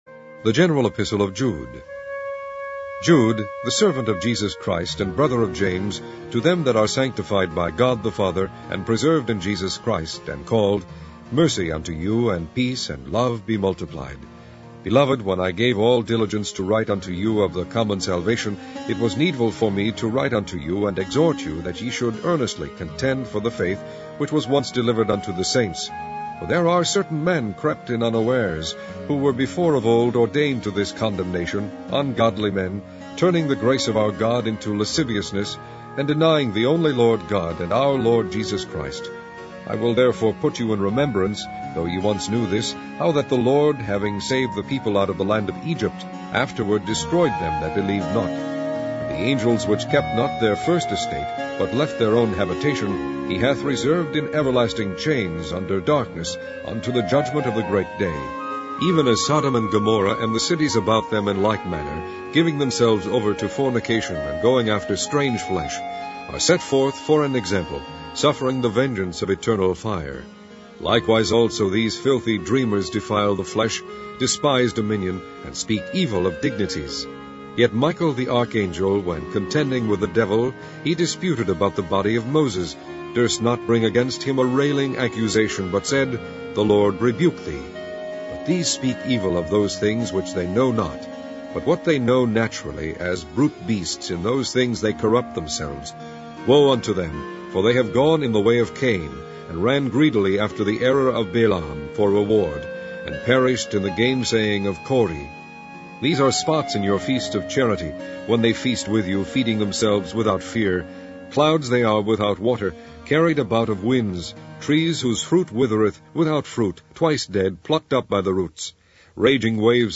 Primitive Baptist Digital Library - Online Audio Bible - King James Version - Jude